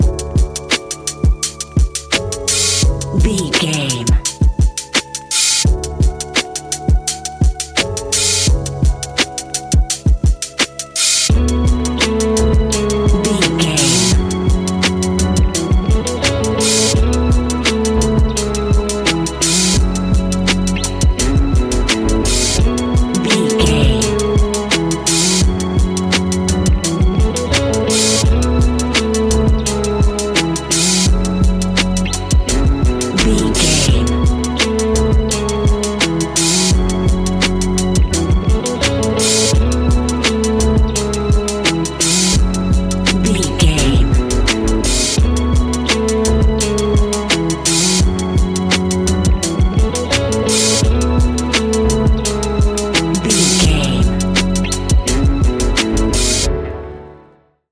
Ionian/Major
F♯
laid back
Lounge
sparse
new age
chilled electronica
ambient
atmospheric